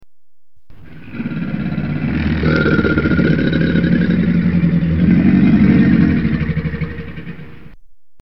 Chinese Alligator sound
Tags: Science/Nature Animals of China Animals Sounds China Giant Panda